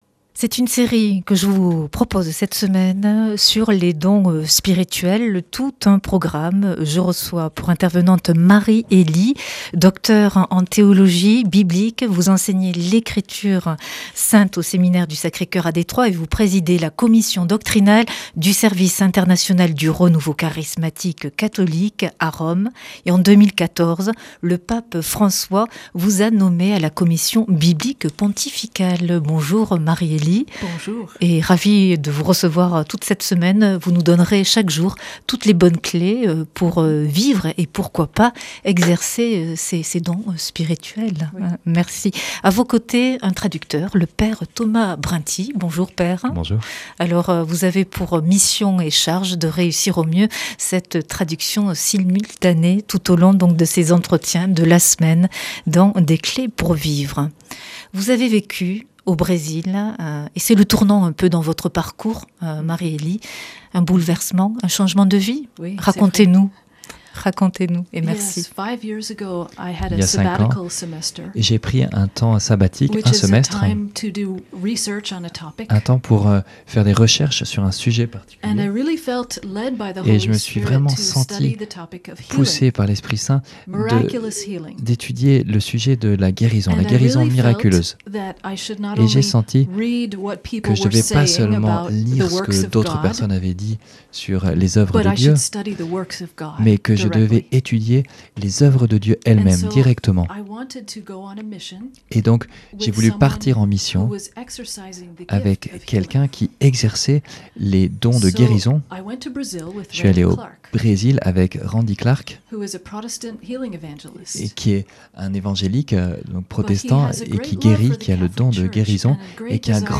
Invitée de la semaine